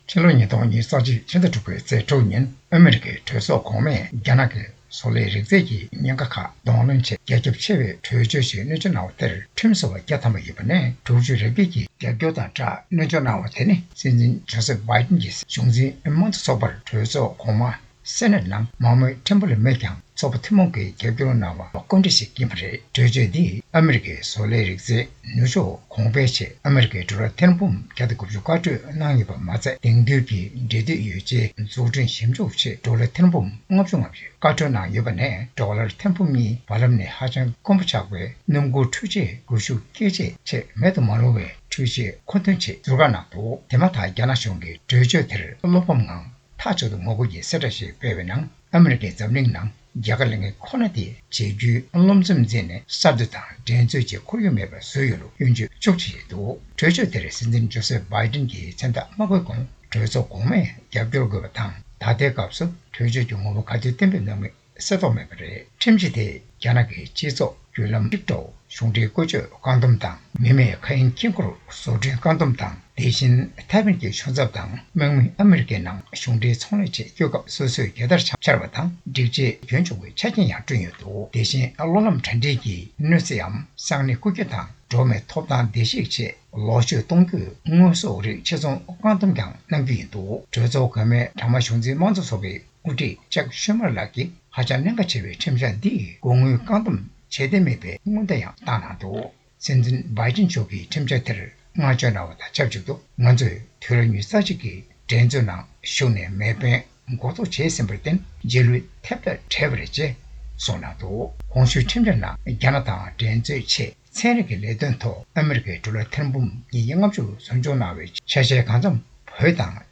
བཀའ་འདྲི་དང་ཕྱོགས་སྒྲིག་ཞུས་པ་ཞིག་གསན་གནང་གི་རེད།།